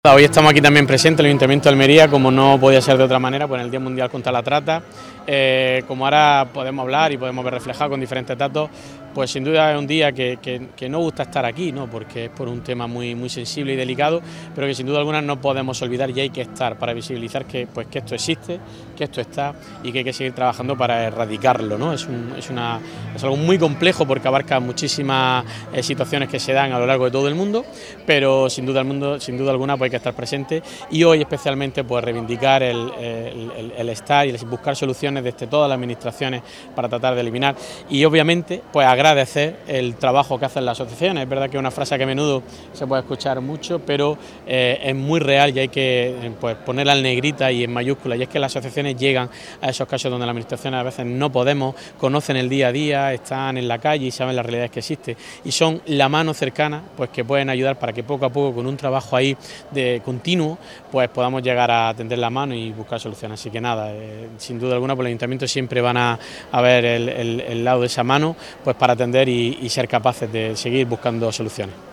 Almería ha unido su voz, con motivo del Día Mundial, para denunciar la explotación sexual y la trata de personas, y lo ha hecho con una serie de mesas informativas seguidas de un acto público celebrado en la plaza Pablo Cazard, donde se ha leído un manifiesto, con una campaña que pone el foco en los peligros de los dispositivos móviles y, esta noche, con el encendido en tono violeta de las fuentes municipales.